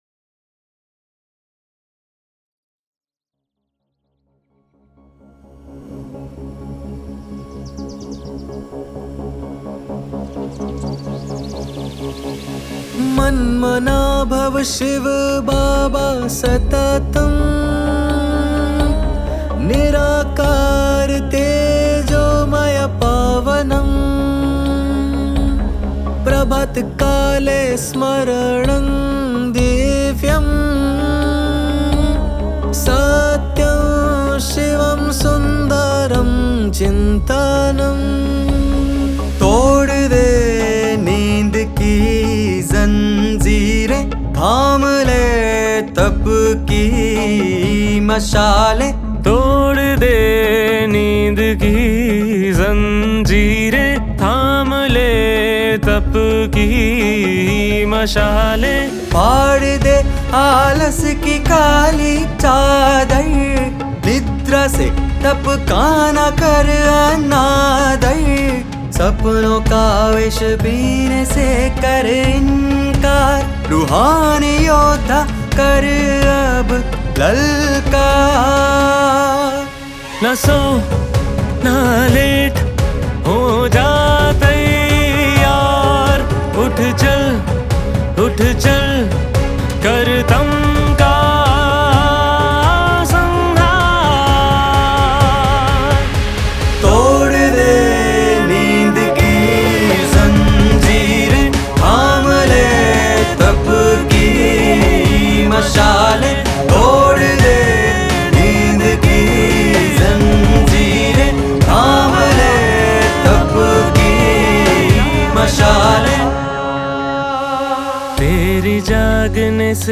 • Speaker: Singer